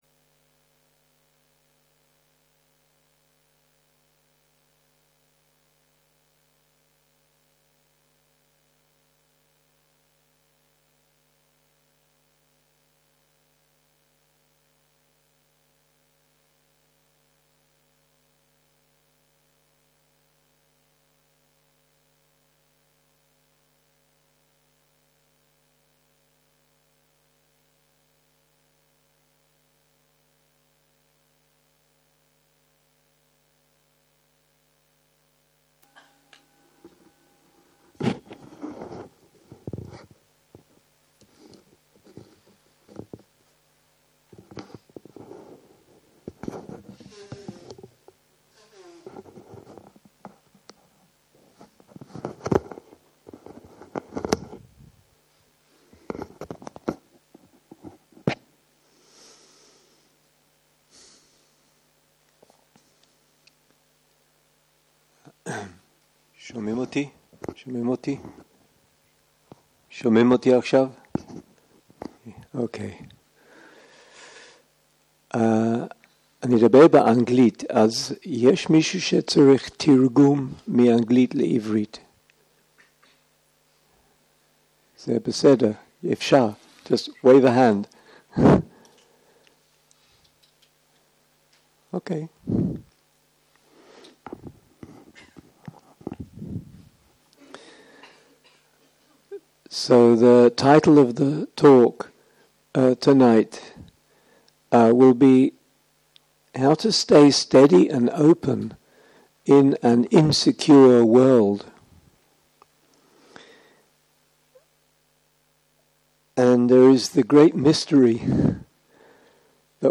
יום 1 - ערב - הנחיות מדיטציה - Staying Steady & Open in an Insecure world - הקלטה 1
סוג ההקלטה: שיחת הנחיות למדיטציה